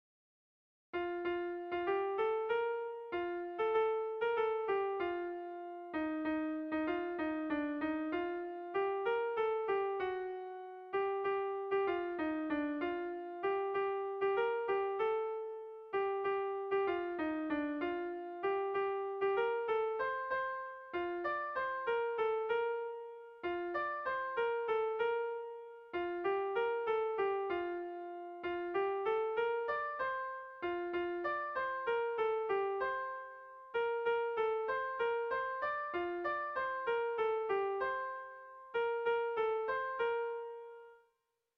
ABDE...